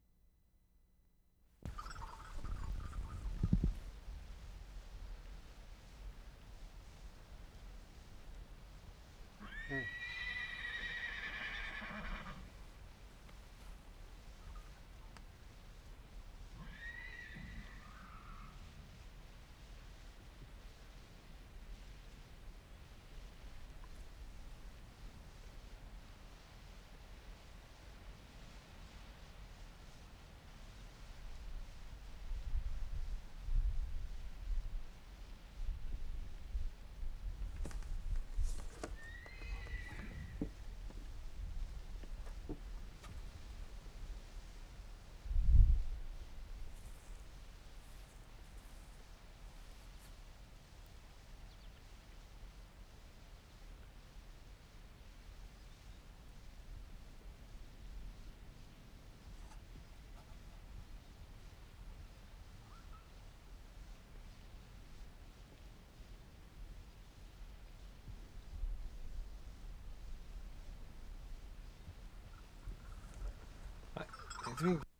WORLD SOUNDSCAPE PROJECT TAPE LIBRARY
ALLIANCE, ALBERTA Sept. 23, 1973
HUSE FARM, preparations to horse feeding 1'24"
2. Horse whinnying at beginning.